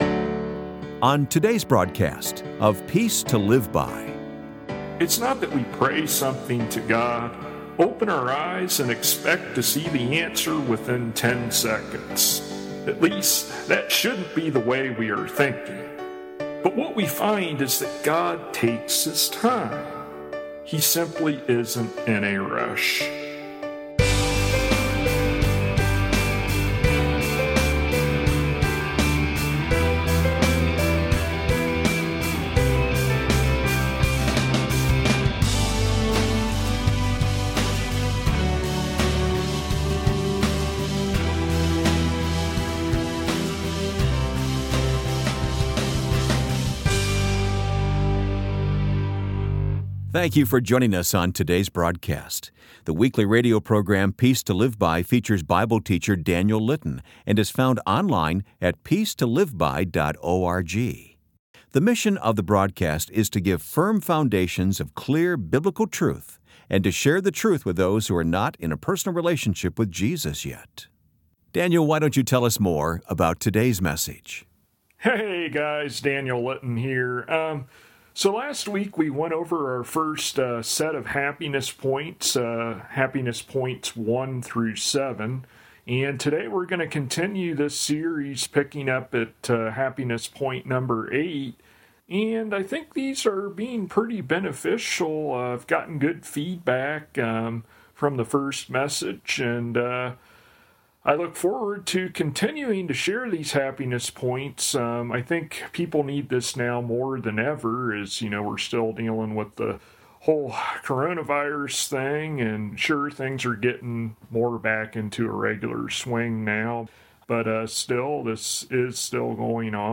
[Transcript may not match broadcasted sermon word for word]